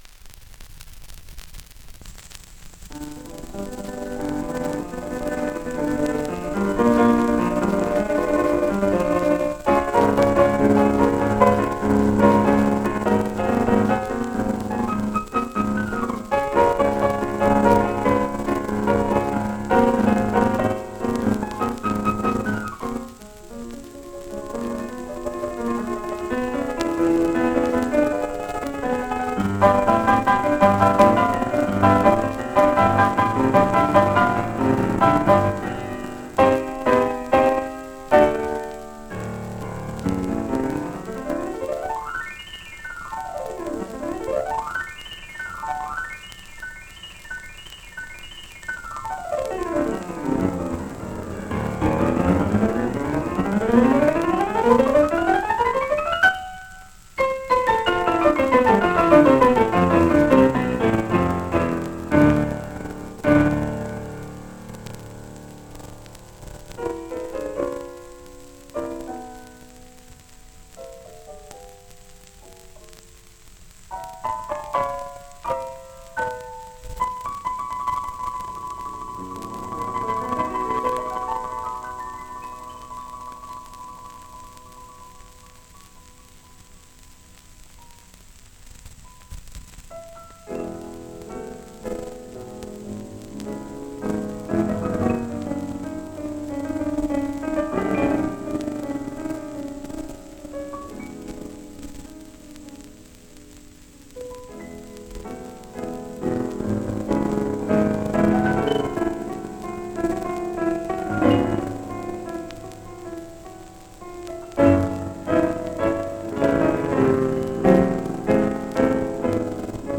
78 rpm